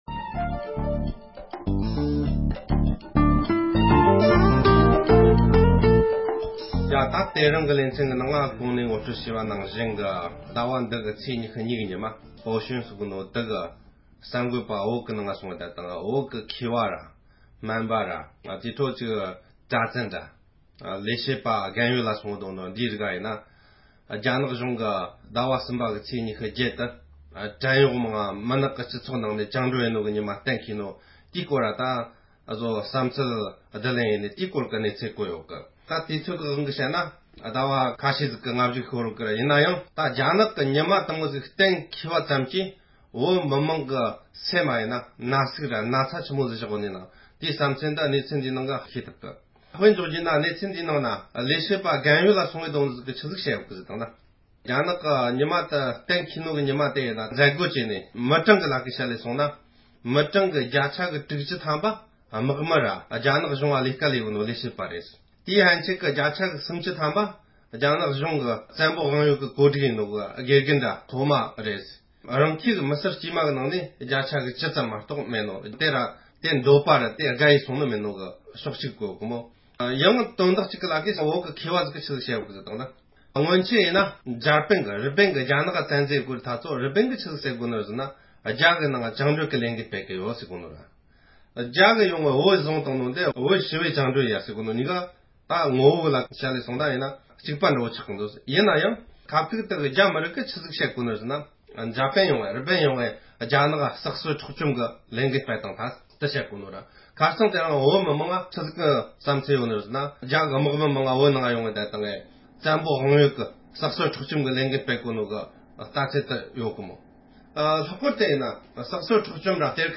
དཔྱད་གཏམ་སྤེལ་བ་ཞིག